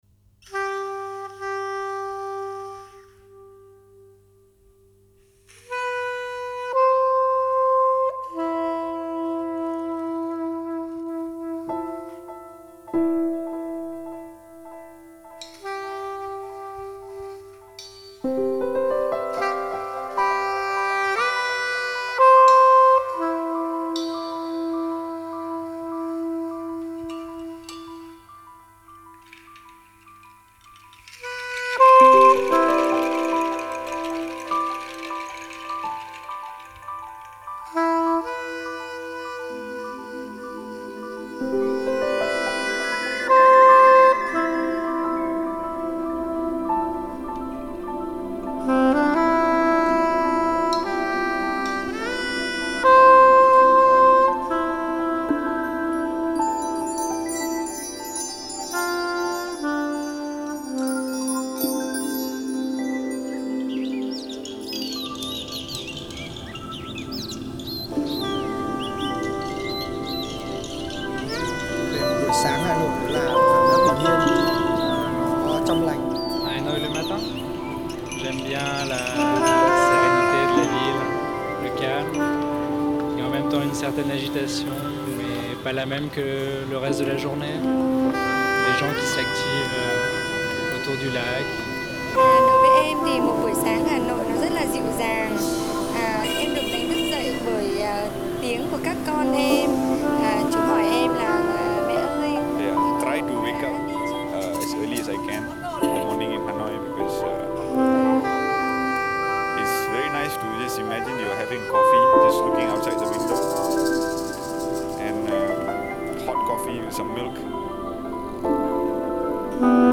and music, soft and smooth and delicate as 6am,